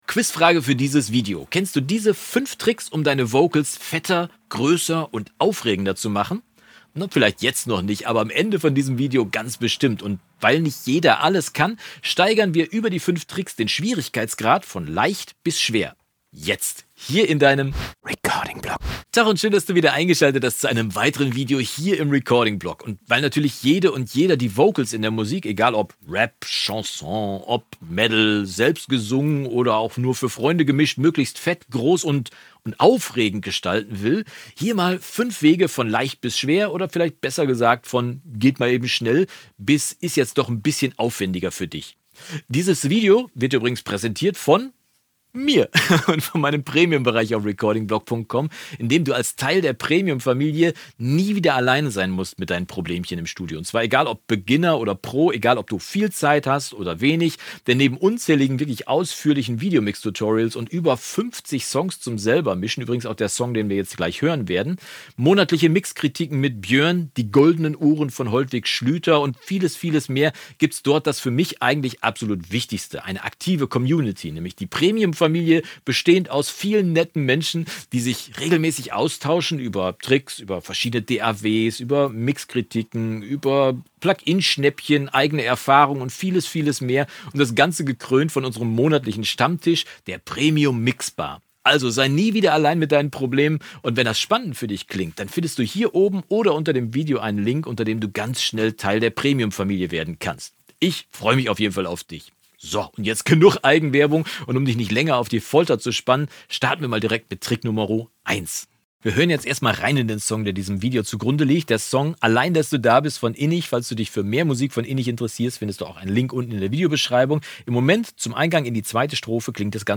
Letzte Episode 5 Tricks für fette Vocals ohne Doppeln | Tutorial | Recording-Blog MP170 11.